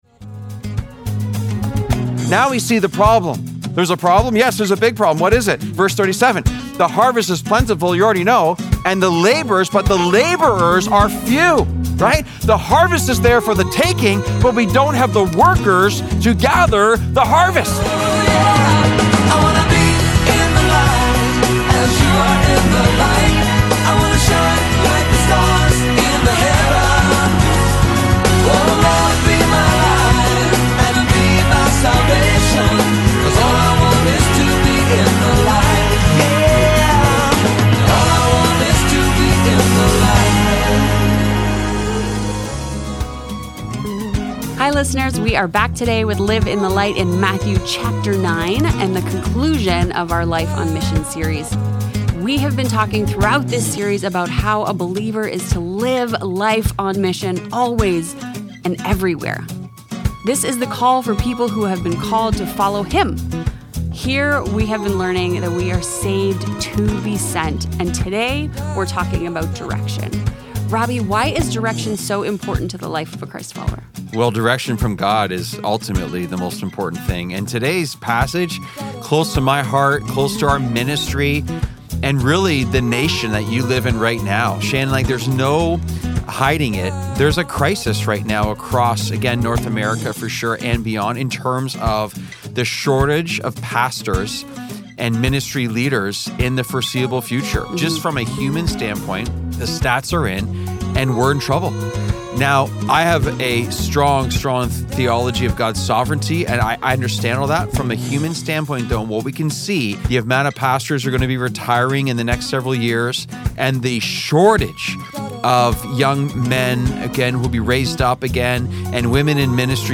Daily Broadcast